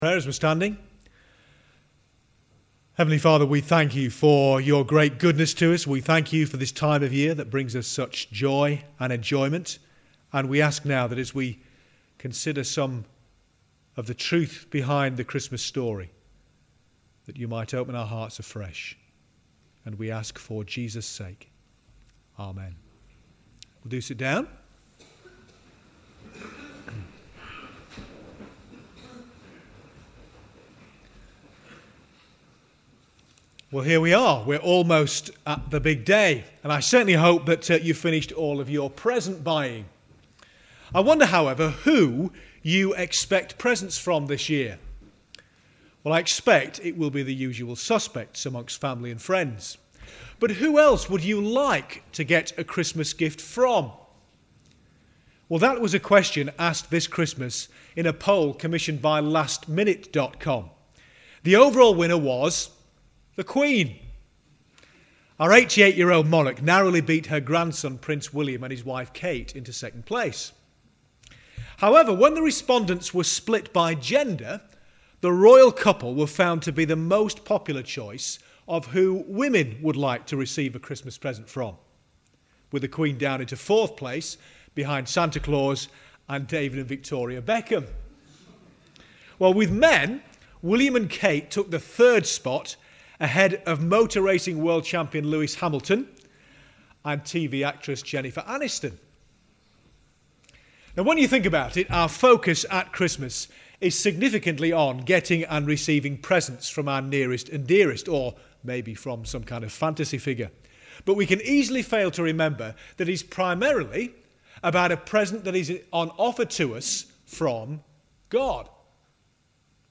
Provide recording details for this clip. Midnight Holy Communion – Readings from Isaiah 9:2-7 and Luke 2:8-17